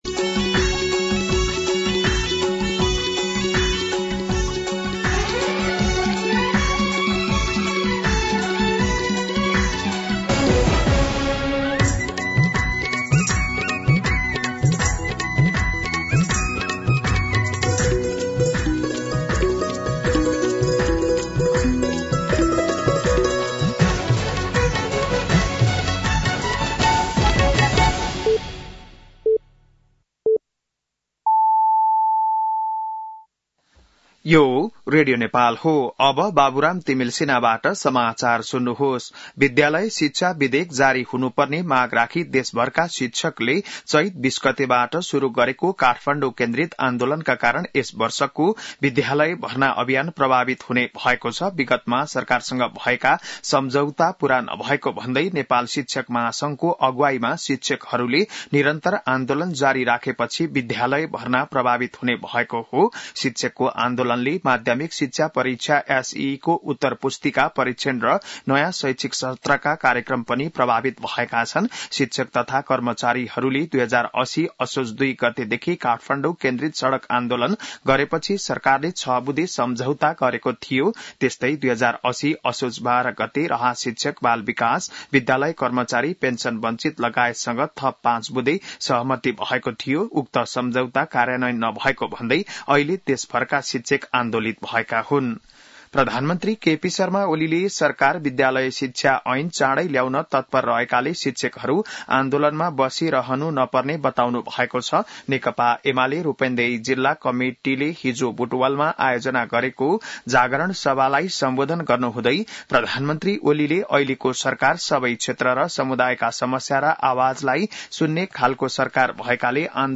बिहान ११ बजेको नेपाली समाचार : ३० चैत , २०८१
11-am-Nepali-News-2.mp3